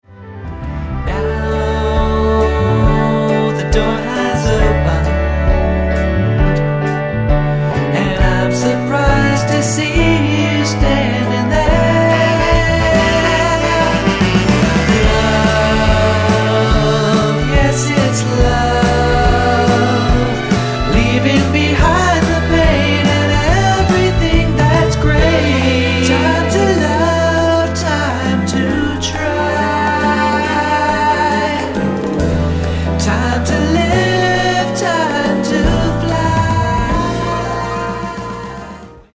SOFT ROCK/GUITAR POP
ソフトロック、ボサにギターポップまで、全てを取り込み鳴らされる魅惑のポップサウンド。